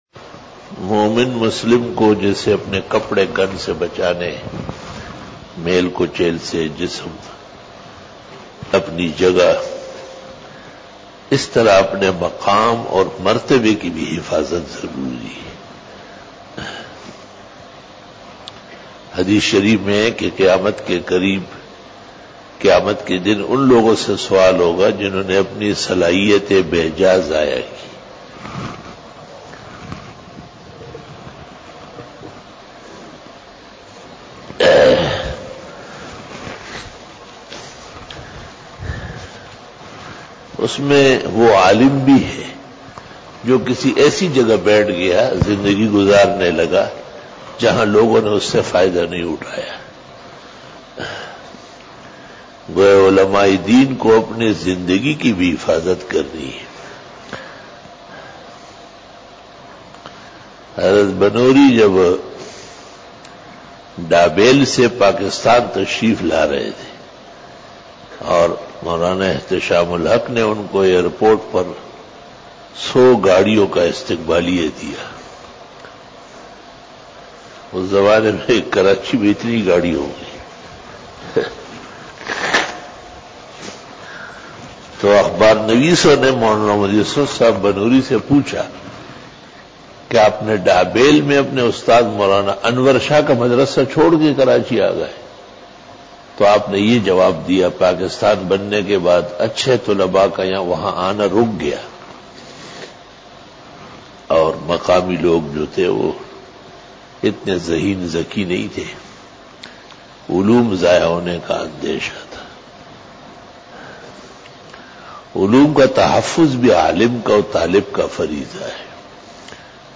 After Fajar Byan
بیان بعد نماز فجر